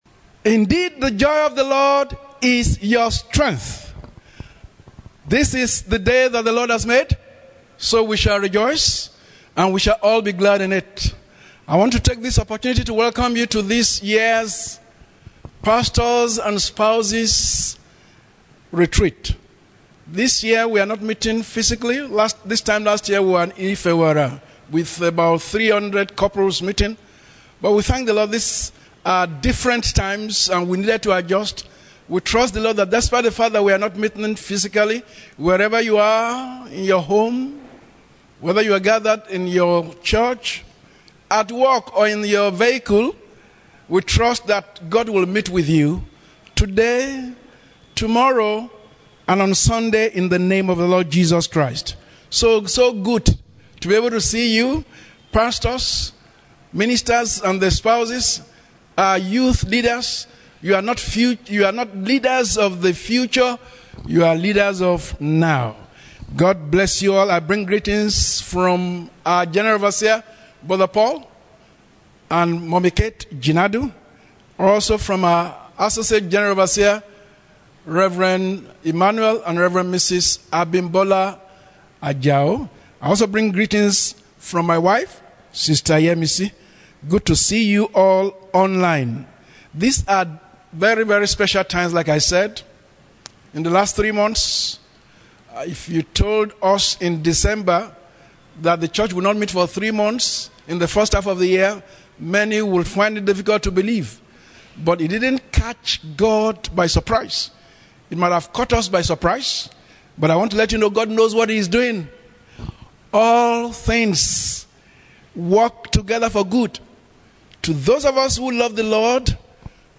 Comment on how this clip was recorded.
The Church in Contemporary Times (Day one, NCC Nigeria National Pastors and Spouses Retreat 2020